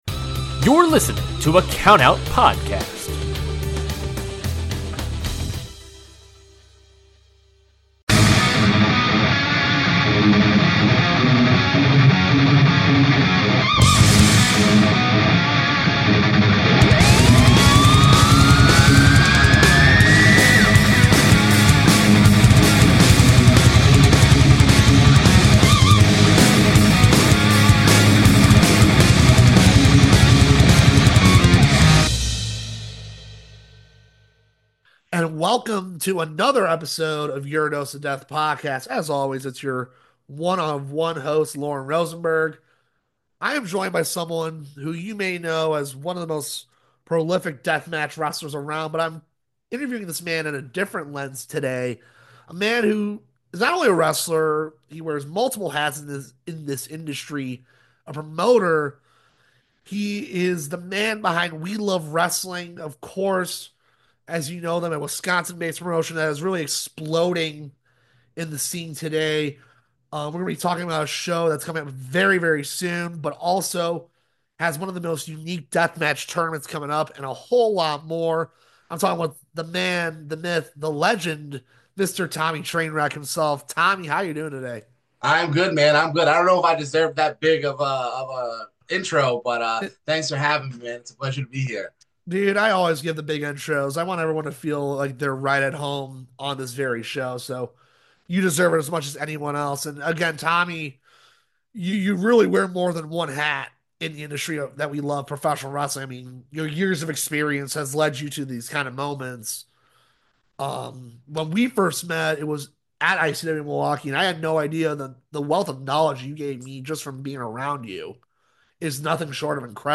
Your Dose of Death is a podcast dedicated to showing a new lens into the world of deathmatch, interviewing those wrestlers and promoters, and finding the love behind the death.